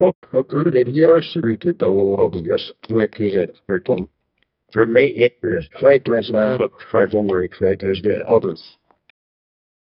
Free AI Sound Effect Generator
shine-shimmer-on-teeth-lvmom2vw.wav